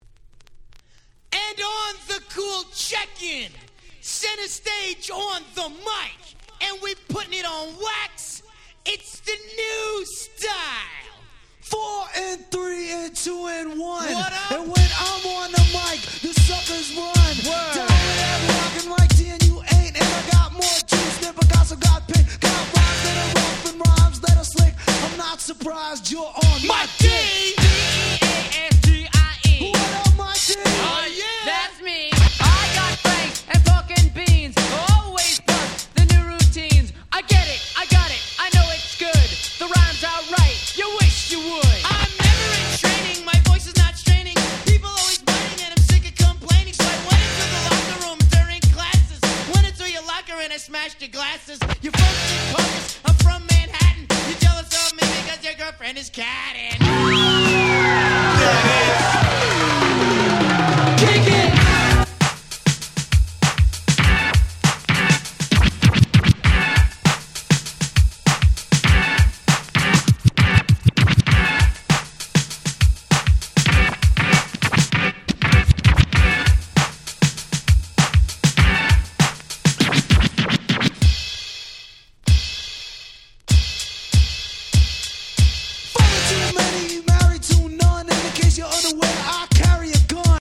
86' Old School Hip Hop Super Classics !!